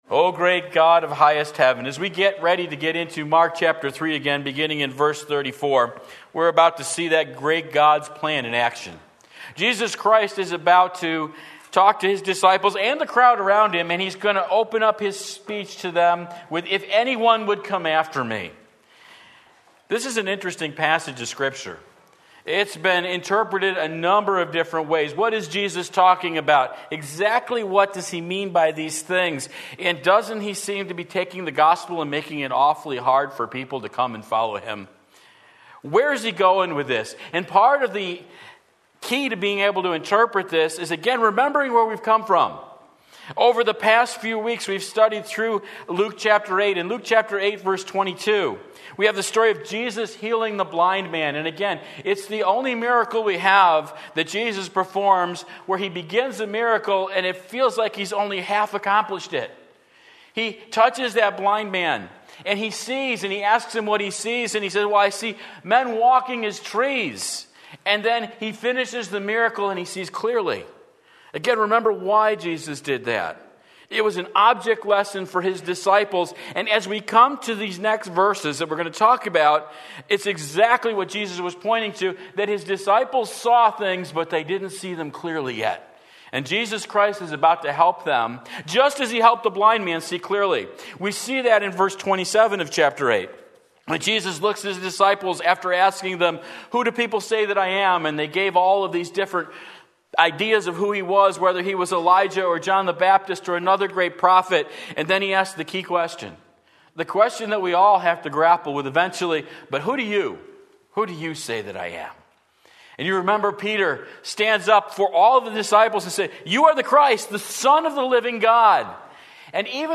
Sermon Link
9:1 Sunday Morning Service